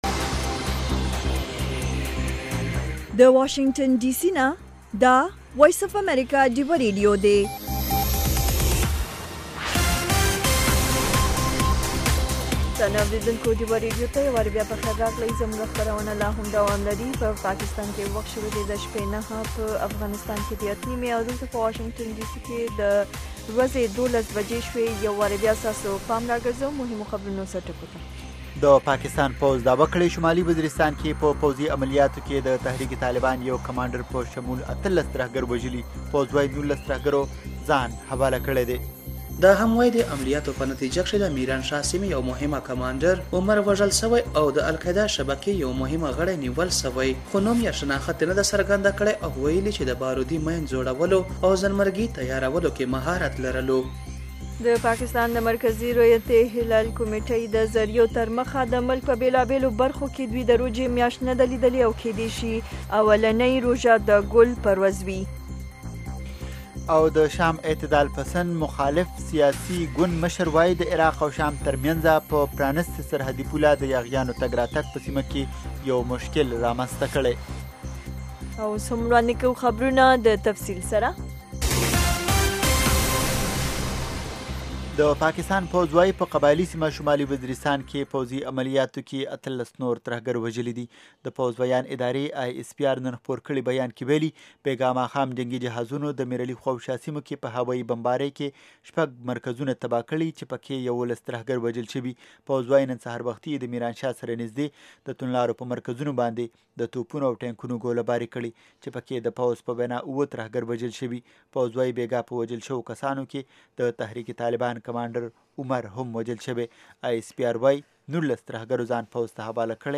دا یو ساعته خپرونه خونده ورې سندرې لري میلمانه یې اکثره سندرغاړي، لیکوالان، شاعران او هنرمندان وي. مهال ويش هره ورځ